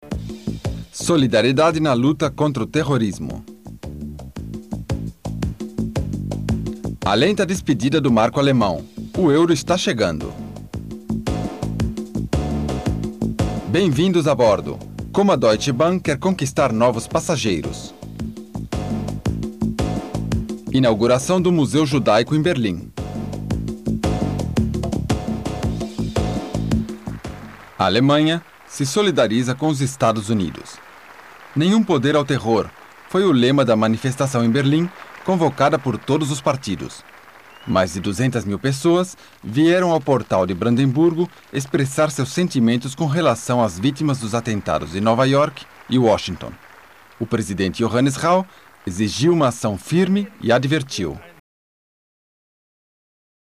Sprechprobe: eLearning (Muttersprache):
i am a Voice Over (Brazil) with many years of experience in Germany.